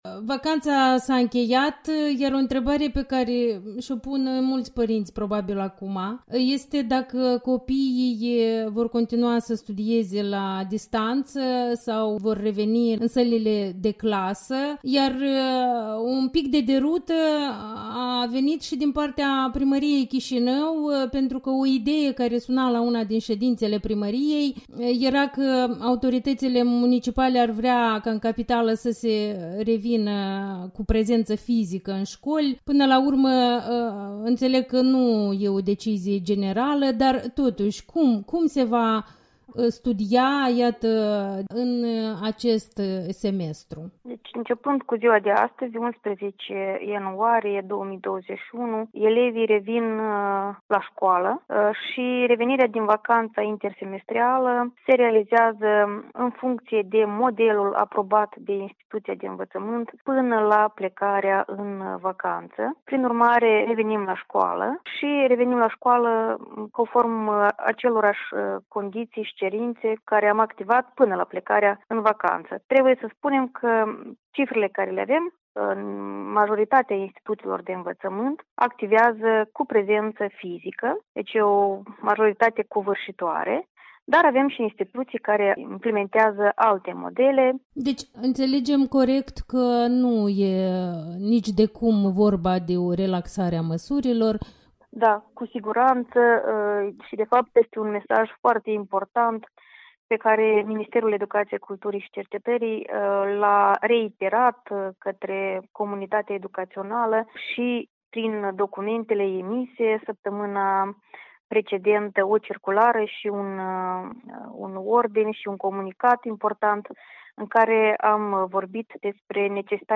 Interviul matinal cu Natalia Grîu, secretar de stat în Ministerul Educației